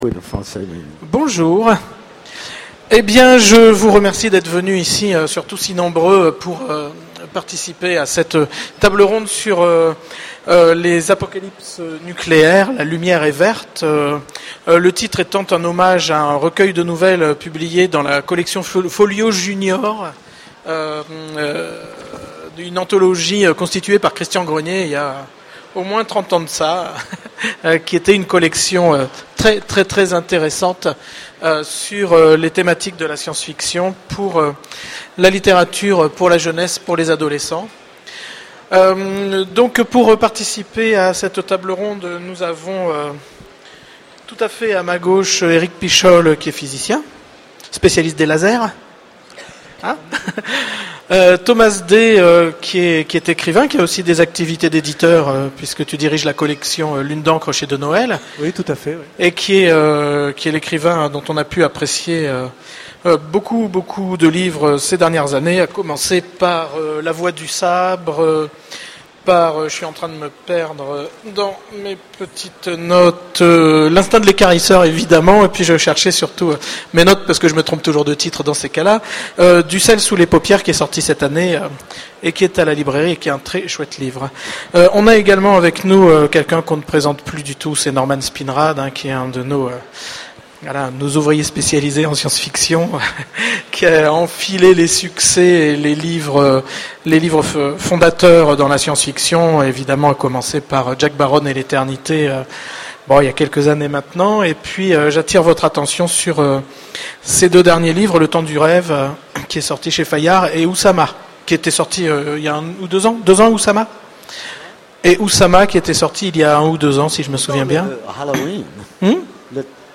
Utopiales 12 : Conférence La Lune est verte !